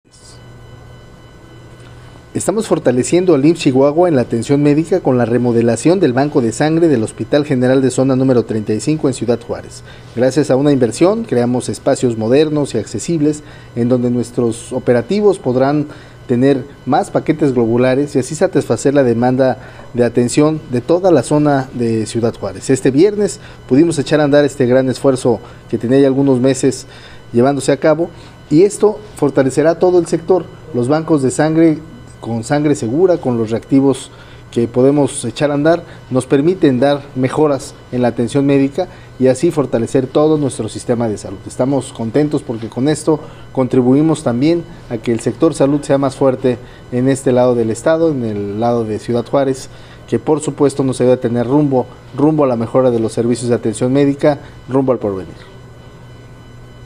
Lo anterior forma parte de los proyectos sustanciales y permanentes que lleva a cabo la Institución, para el fortalecimiento de los servicios en la entidad, informó el titular del IMSS en el estado, doctor Enrique Ureña Bogarín, en una sencilla ceremonia que se llevó a cabo para reanudar este servicio.